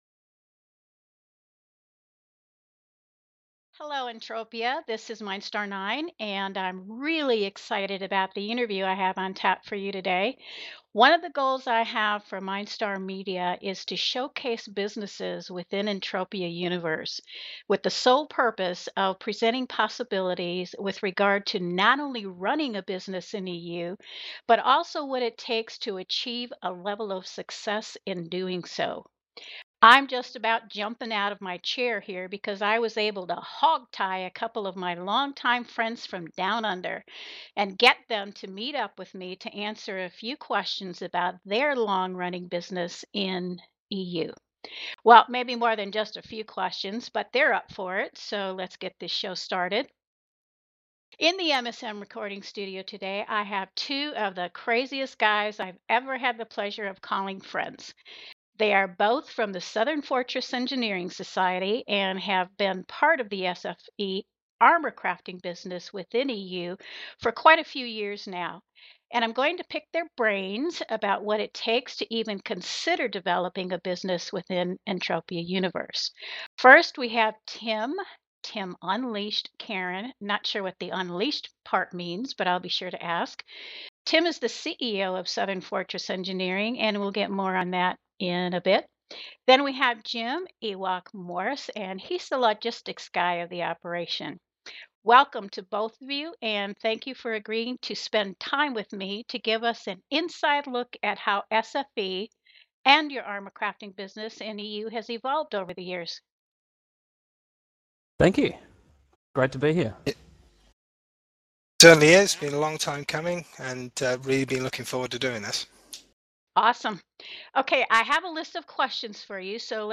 MindStar Media interview with Southern Fortress Engineering.